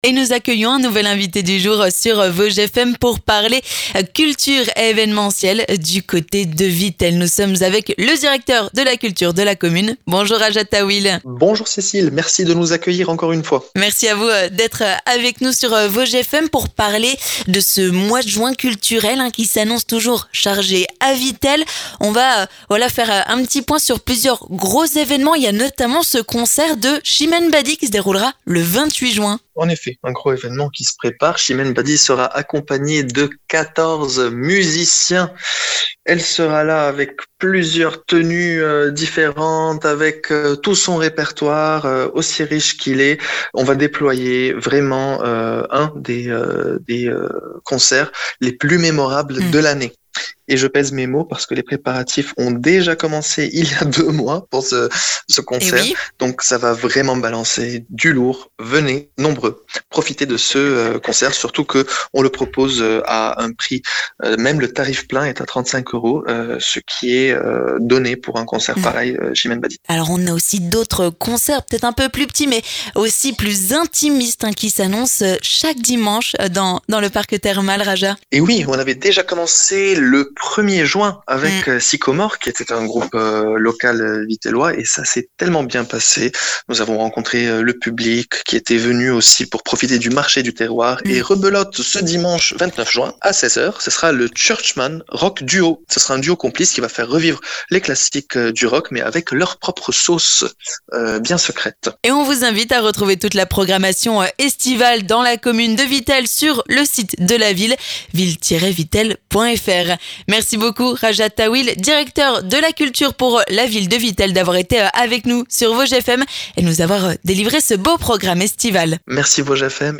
L'invité du jour